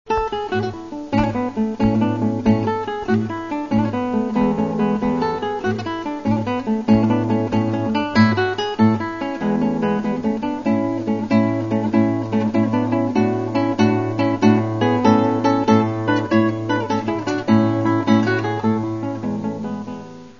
guitaesp.wav